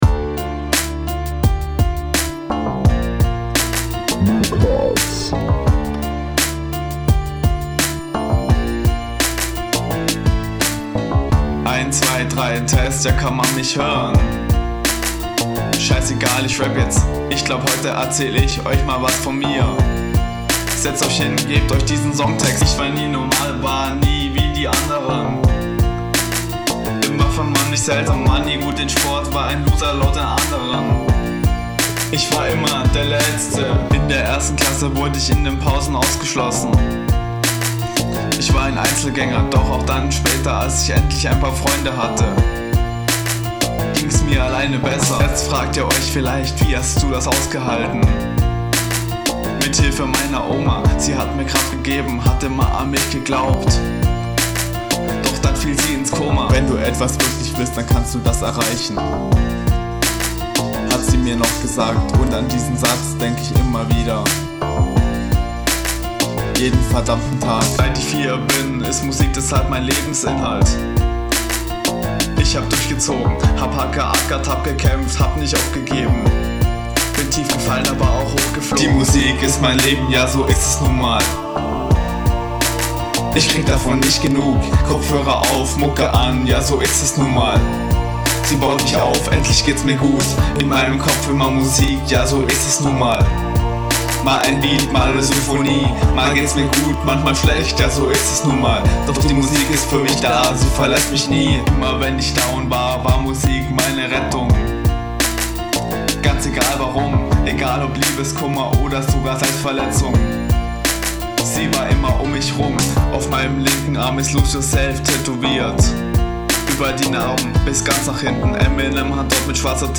Hi, bin schon länger am Producer aber habe noch nie selber dazu gerappt, deswegen bin ich da noch kompletter Anfänger.